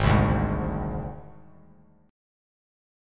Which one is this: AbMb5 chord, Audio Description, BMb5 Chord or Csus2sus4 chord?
AbMb5 chord